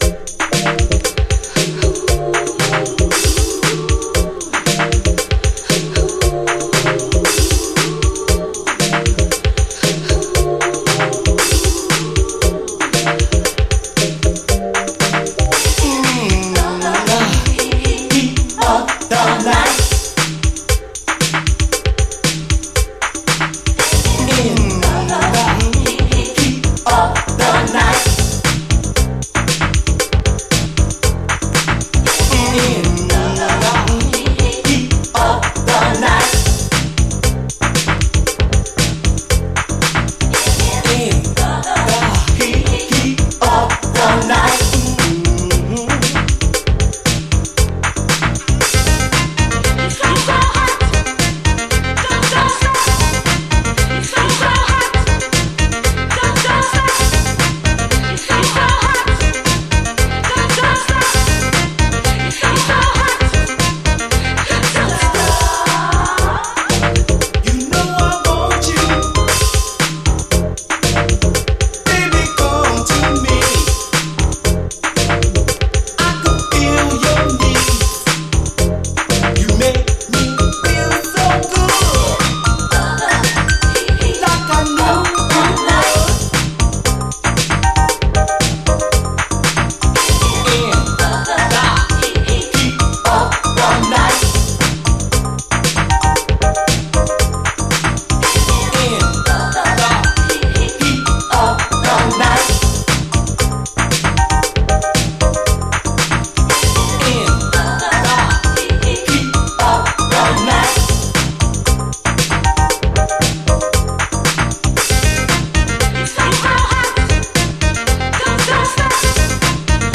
DISCO
クールな質感がカッコいいガラージ・クラシック！
エレクトロとモダン・ブギーの中間のような、熱くならないメロウかつクールな質感がカッコいいです。